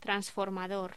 Locución: Transformador
voz